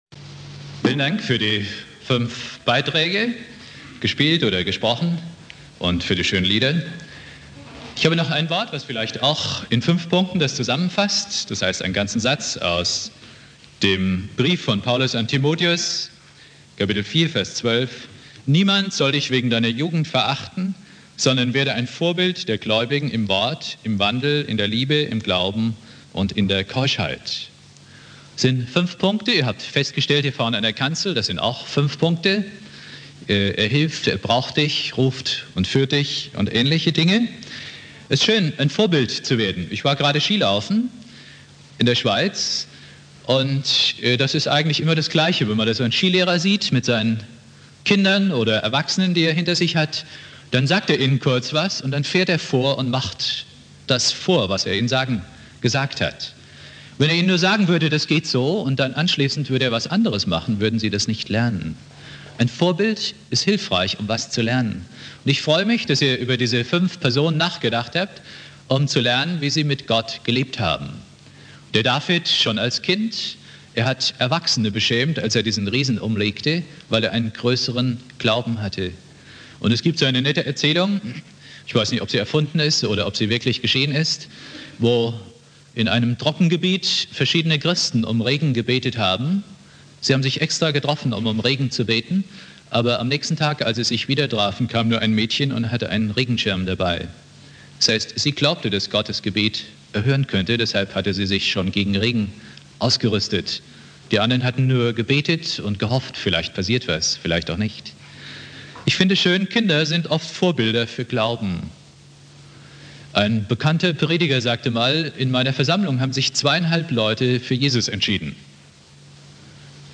Thema: Kurzpredigt zum Thema "Vorbilder" Bibeltext